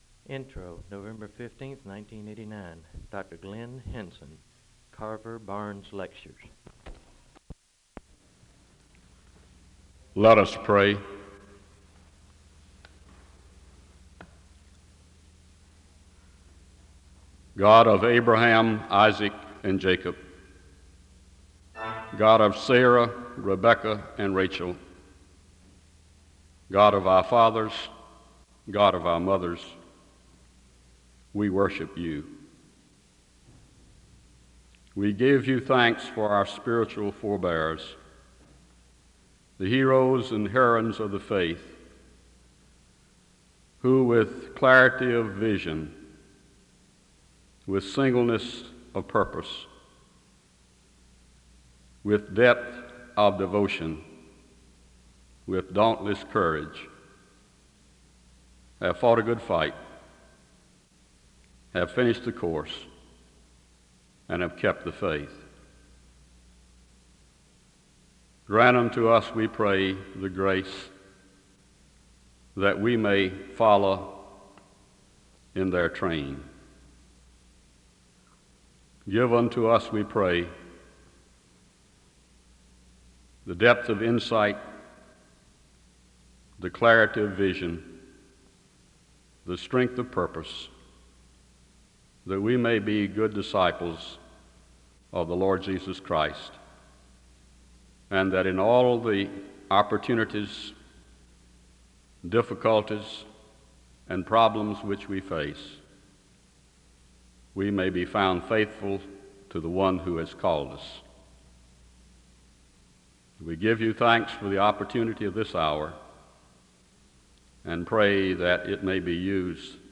SEBTS Carver-Barnes Lecture
SEBTS Chapel and Special Event Recordings SEBTS Chapel and Special Event Recordings